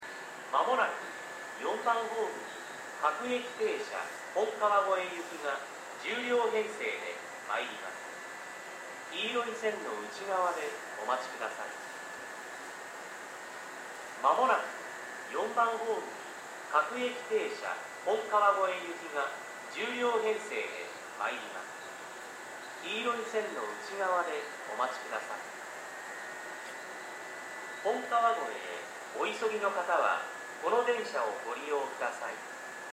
音量は大きめです。スピーカーも設置されており音質もそこそこです。
接近放送各駅停車　本川越行き接近放送です。